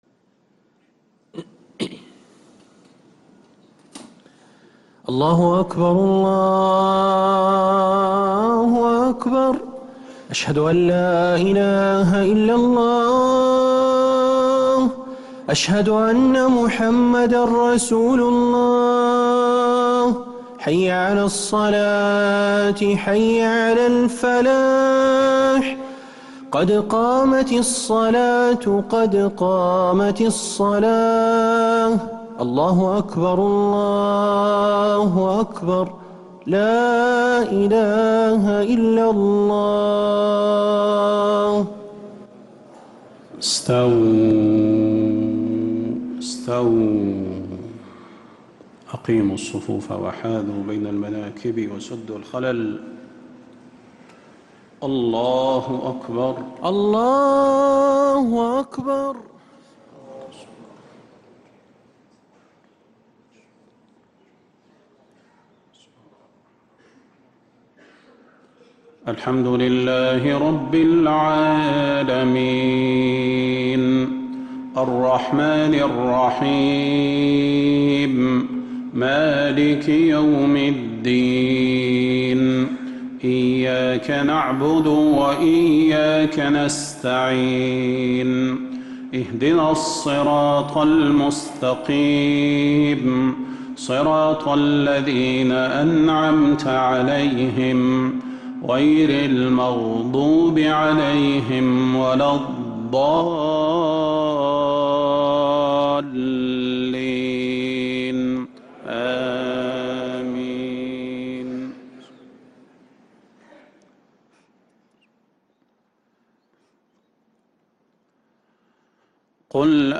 Haramain Salaah Recordings: Madeenah Maghrib - 19th April 2026